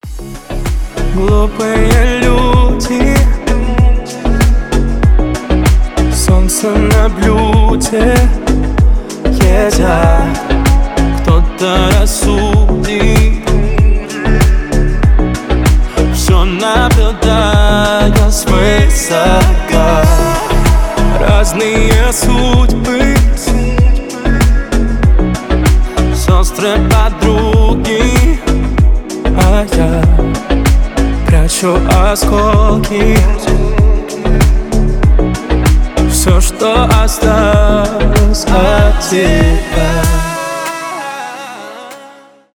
поп , cover
спокойные